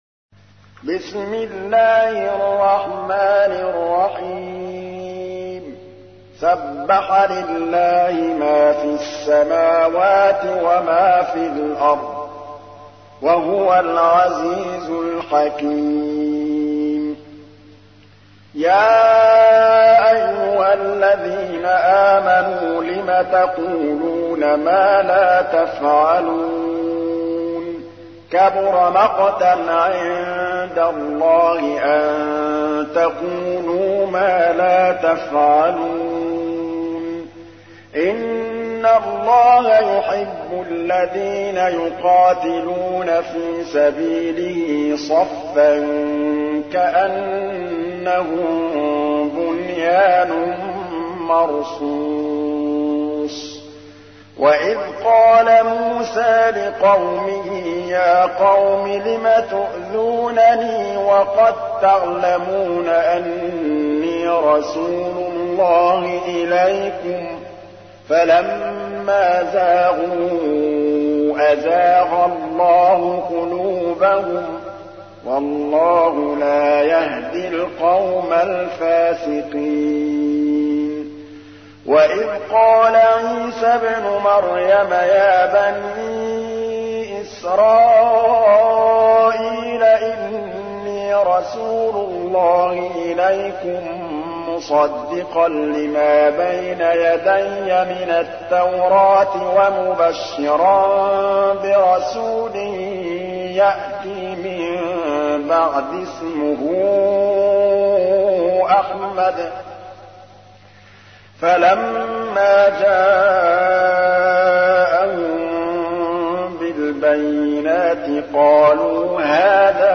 تحميل : 61. سورة الصف / القارئ محمود الطبلاوي / القرآن الكريم / موقع يا حسين